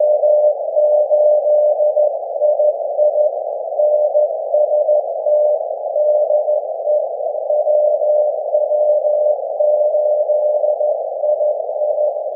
CW nice start at 559 but then a quick 20+ dB fade. peaked near 30 dB over visual noise